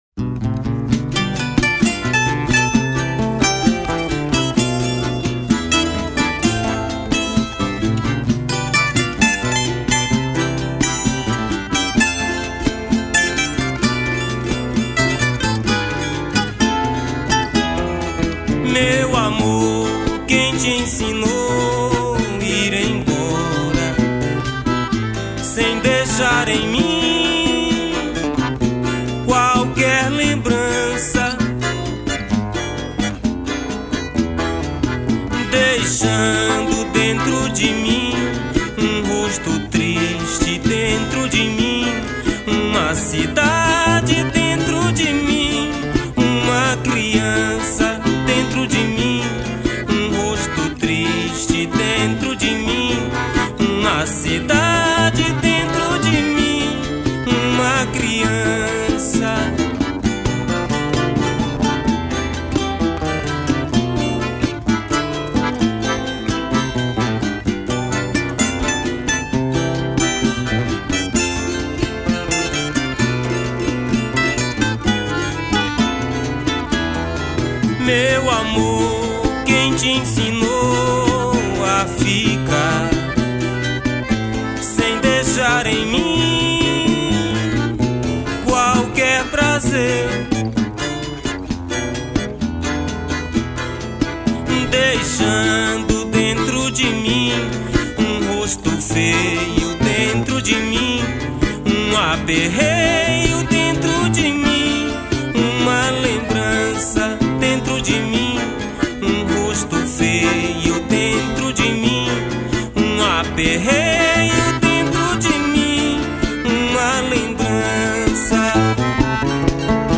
Violao Acústico 6
Violao 7
Acoordeon
Pandeiro
Bandolim